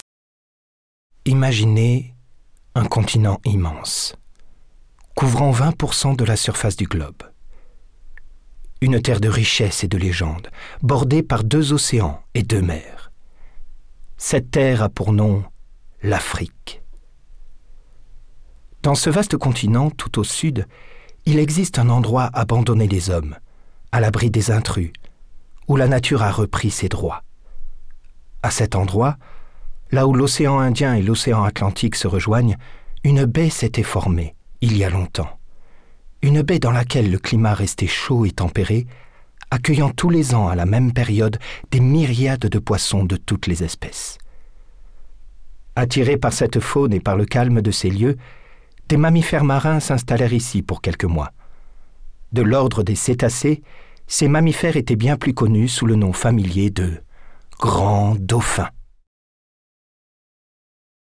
Voix
30 - 50 ans - Baryton-basse